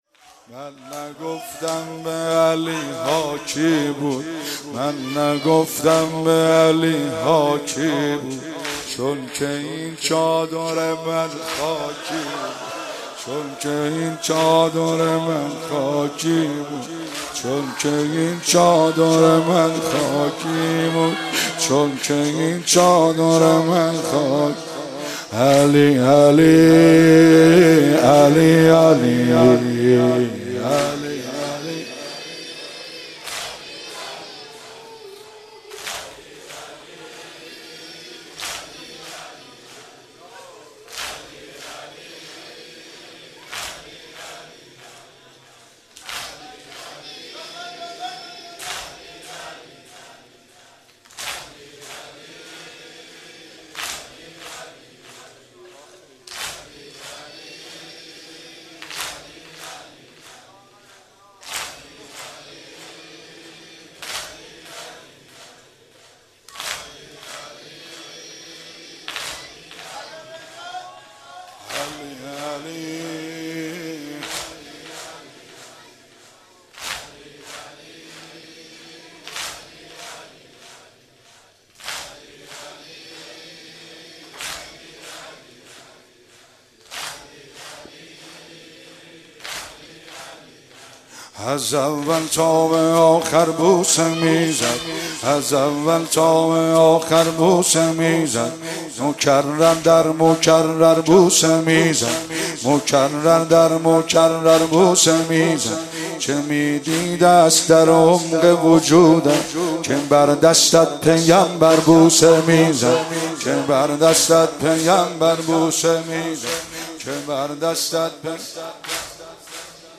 30 بهمن 96 - هیئت فاطمیون - واحد - من نگفتم به